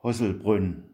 hochdeutsch Gehlbergersch
(Am) Haselbrunn  Hoaselbrönn